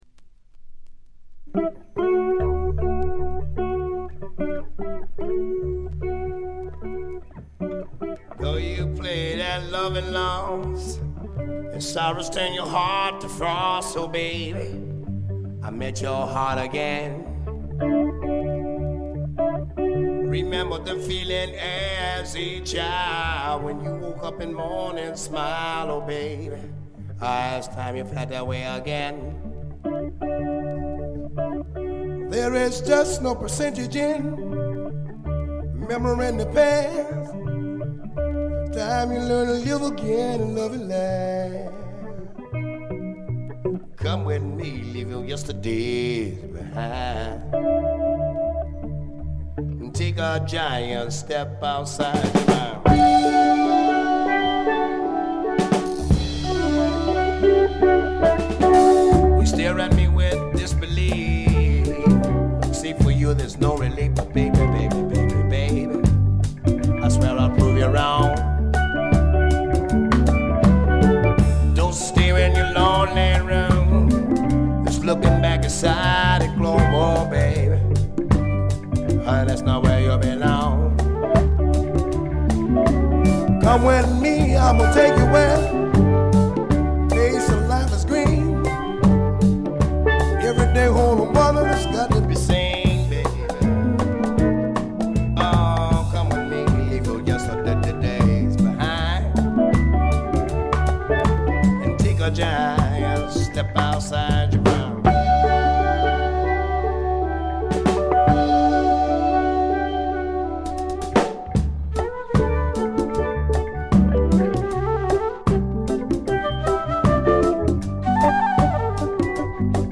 ほとんどノイズ感無し。
試聴曲は現品からの取り込み音源です。
electric guitar, piano, lead vocals